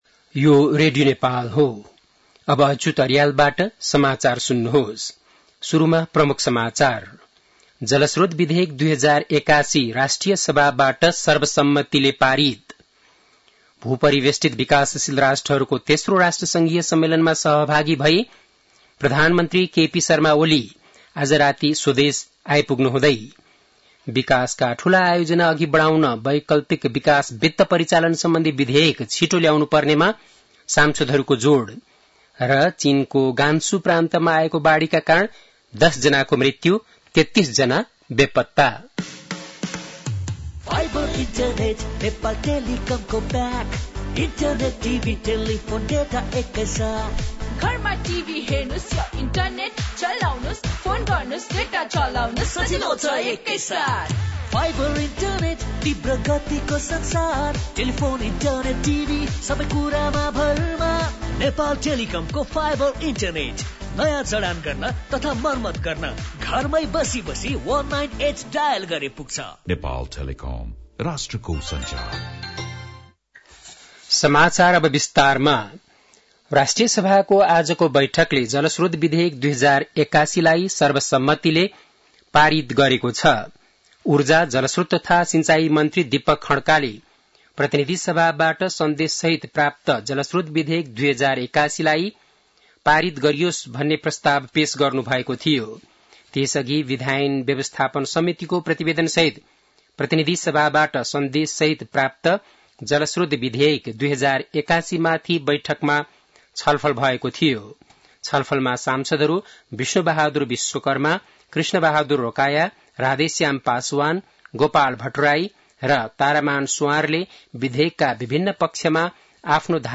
बेलुकी ७ बजेको नेपाली समाचार : २३ साउन , २०८२
7-pm-nepali-news-4-23.mp3